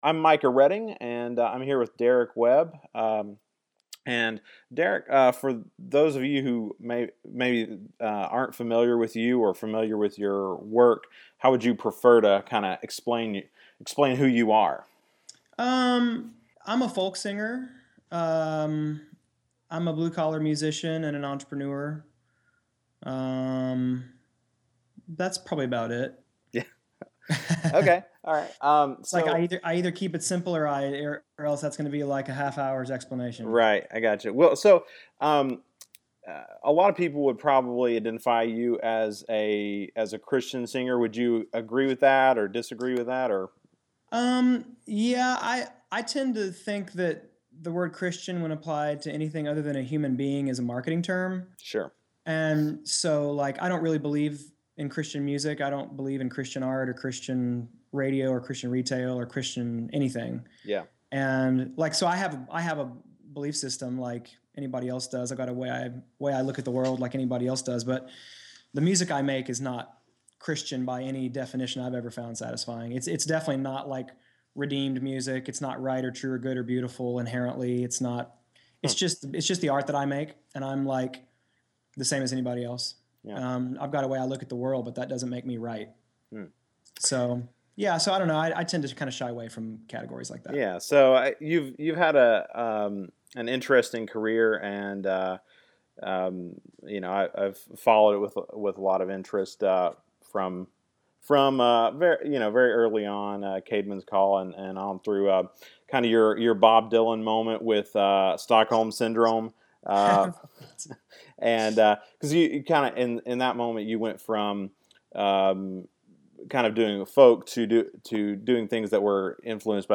In the first part of our interview, Derek Webb introduces us to an album about the dawn of the first AI, and poignantly shows us just how disconnected we're all becoming.